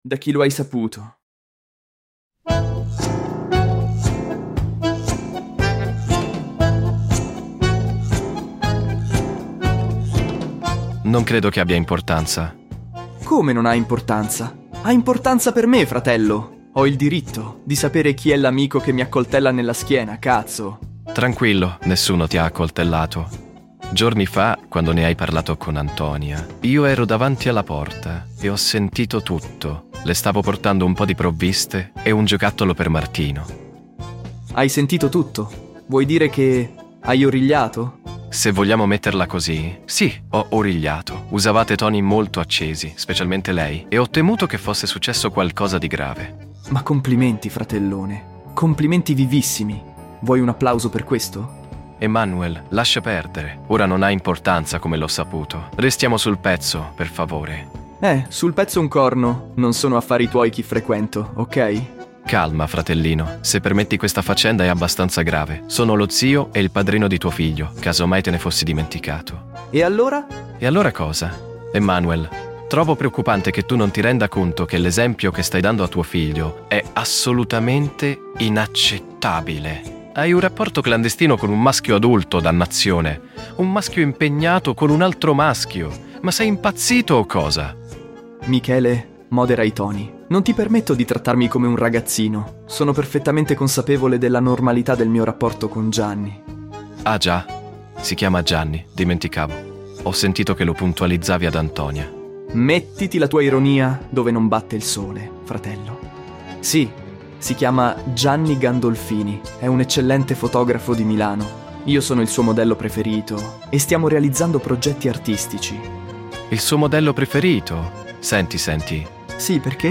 Podcast Novel